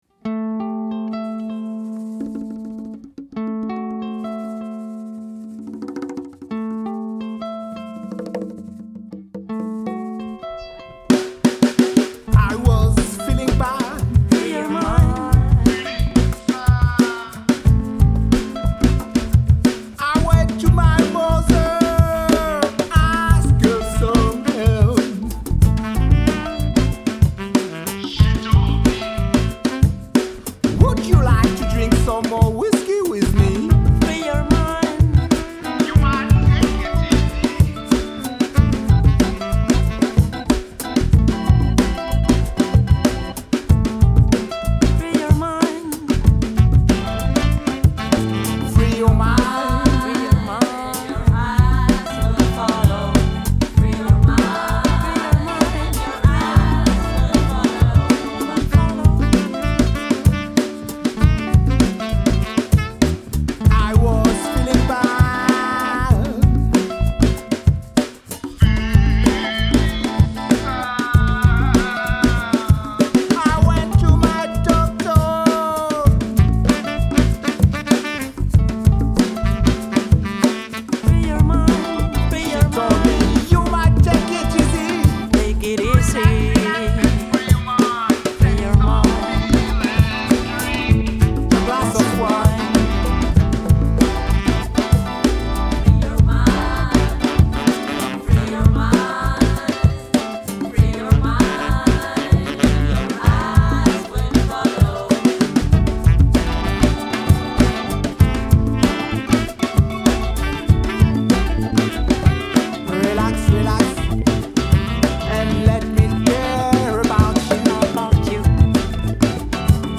piano à roulette